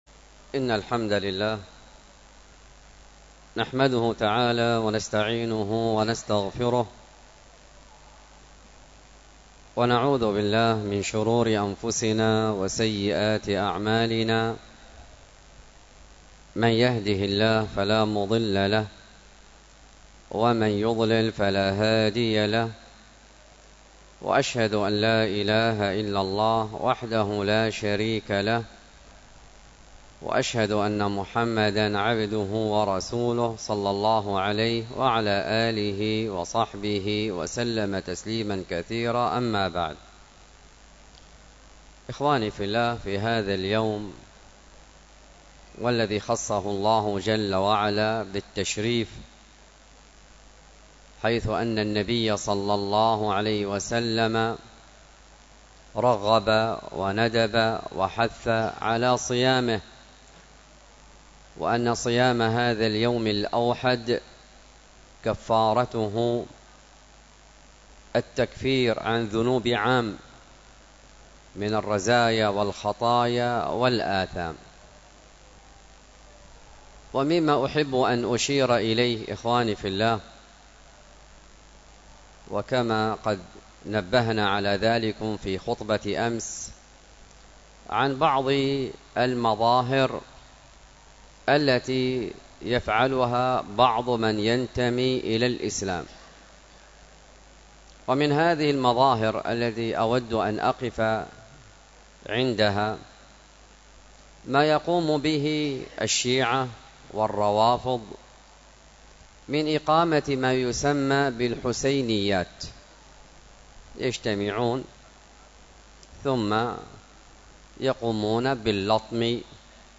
المحاضرة بعنوان عاشوراء وحقائق مقتل الحسين 02، والتي كانت بمسجد التقوى بدار الحديث بالشحر